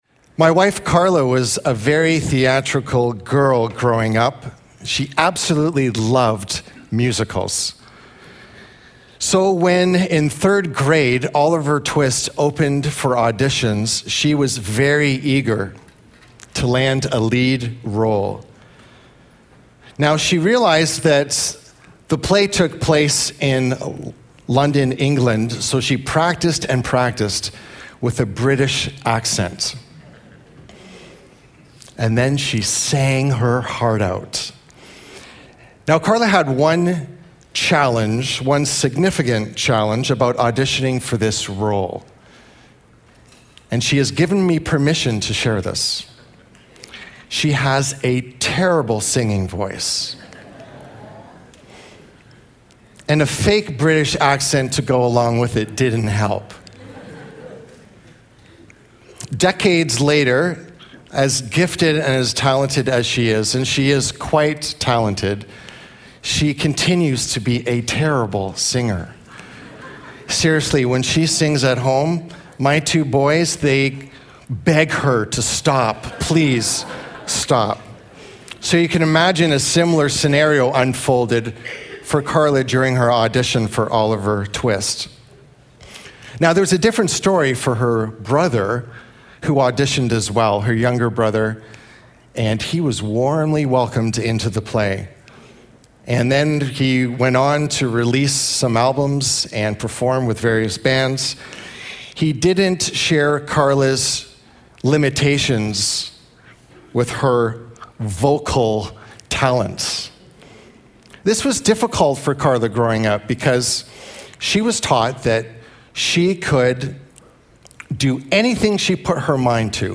Episode from Tenth Church Sermons